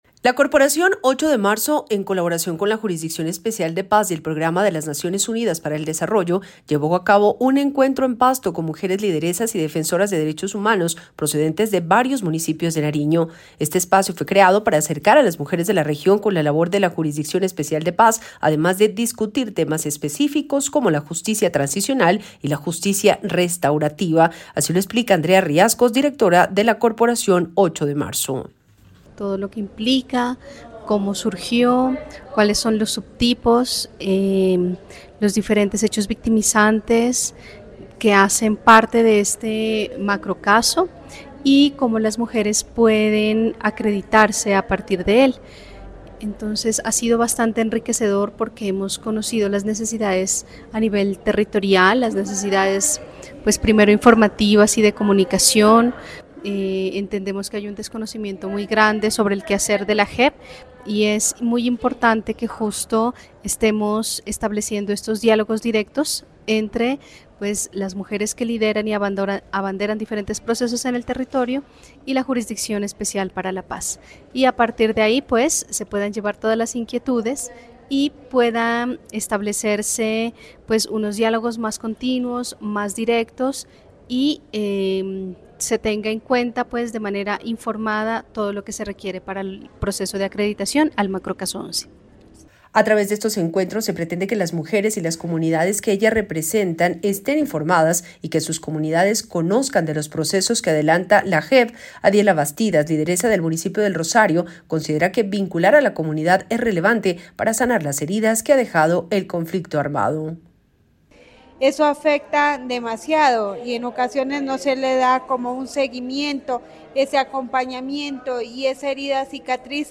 Informe JEP Nariño